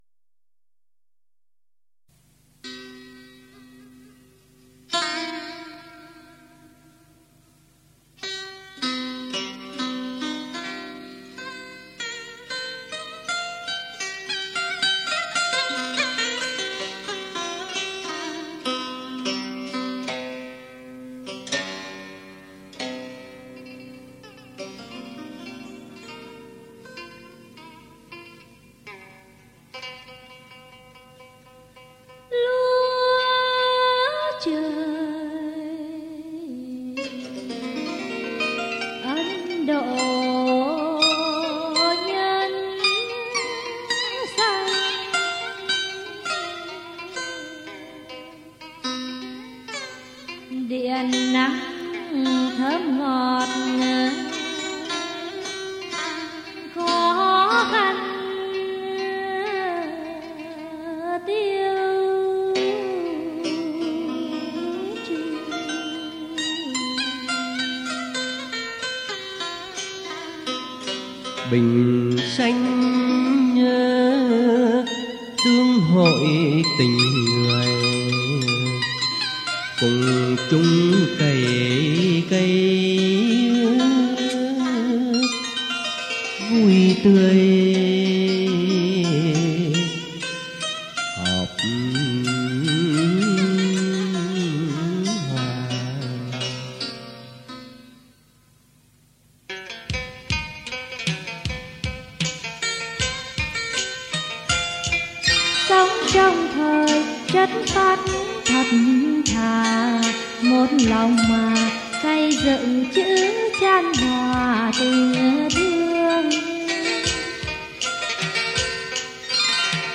theo điệu Trống Quân miền Bắc